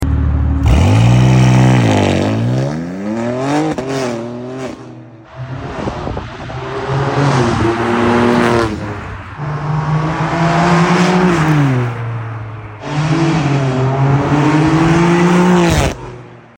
S58 + titanium = pure goosebumps PLM high-flow downpipes with EPA cats + full titanium valved exhaust on this G87 M2 — lighter, freer flowing, and now breathing like it should. The sound? Crisp, deep, and pure BMW aggression, whether the valves are open for track mode or closed for cruising.